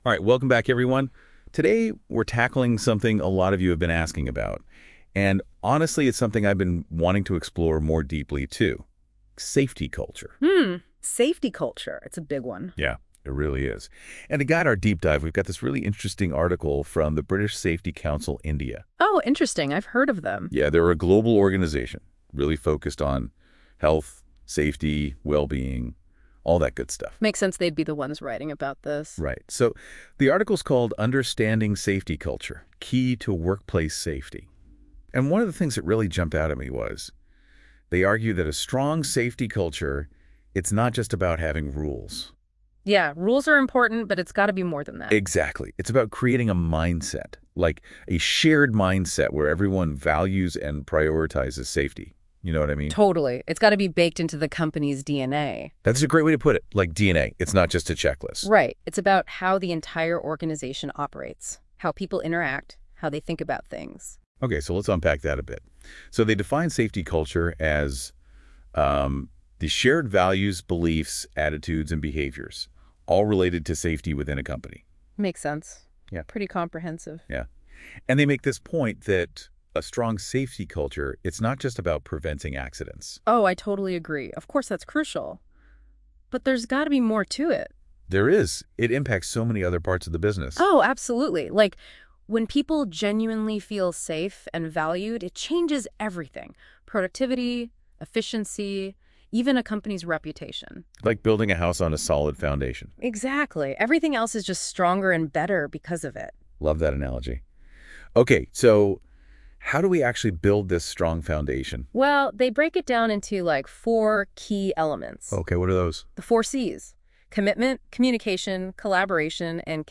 Divers outils (générés par IA)
audio_notebooklm_hygiene_secu_travail.mp3